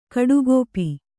♪ kaḍugōpi